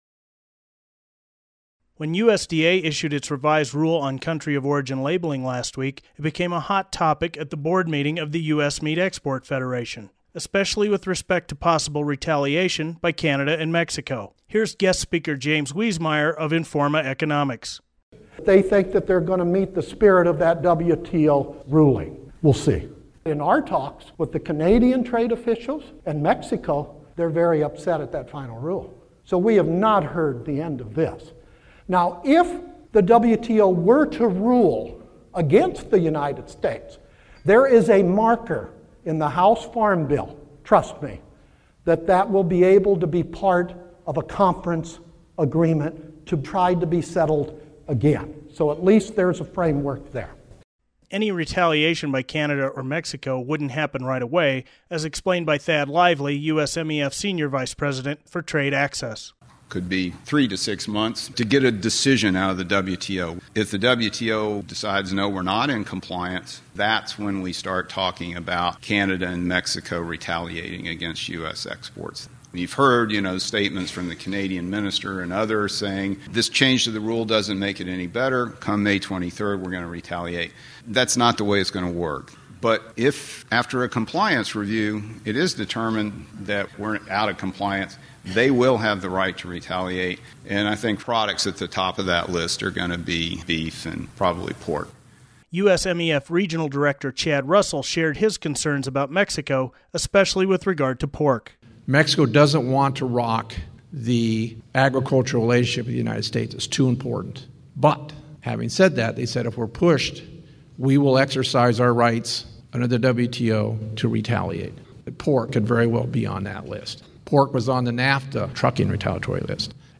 The attached audio report includes the following comments from the USMEF board meeting: